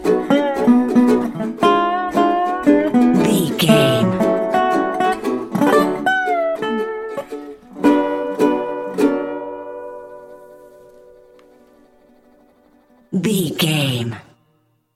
Uplifting
Ionian/Major
acoustic guitar
bass guitar
ukulele
dobro
slack key guitar